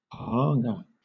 Ääntäminen
IPA : /pɑːˈɑːŋɡə/